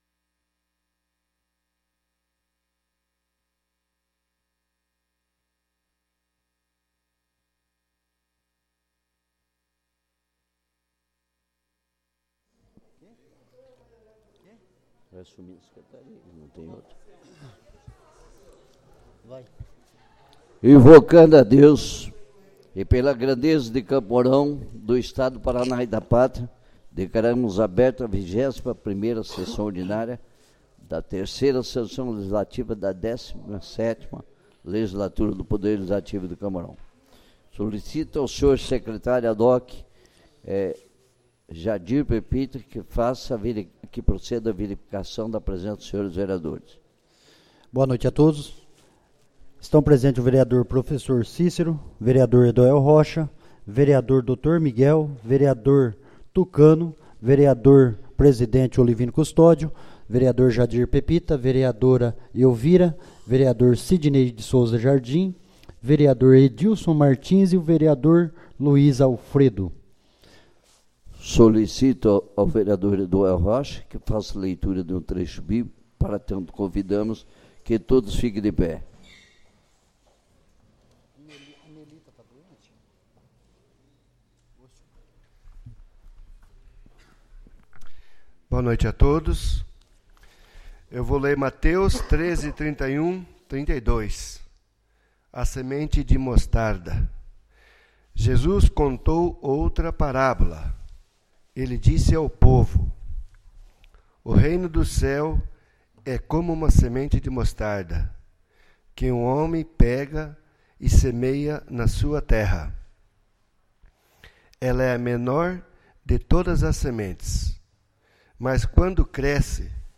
21ª Sessão Ordinária